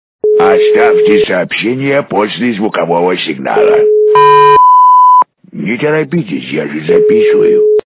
» Звуки » Смешные » Автоответчик - Голос Карлсона - Оставьте сообщение после звукового сигнала. Не торопитесь, я же записываю